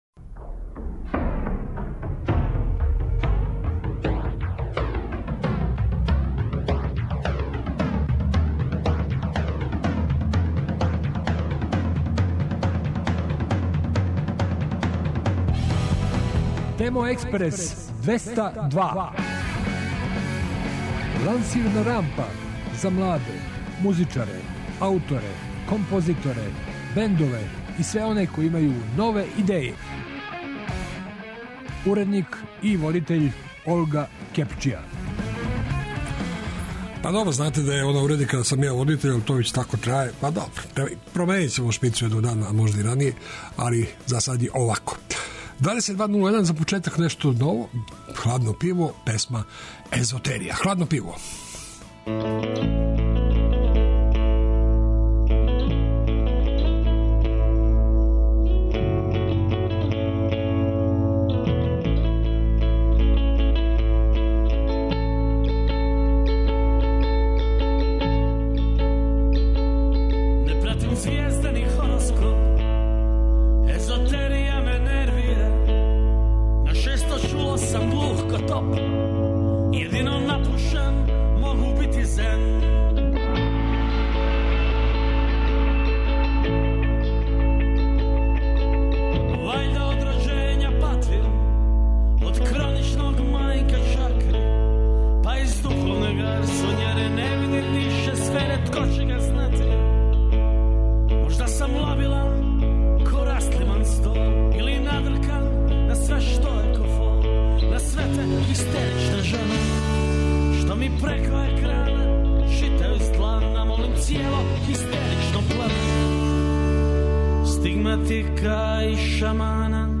И вечерашња емисија доноси много занимљивих тема, гостију и наравно музике.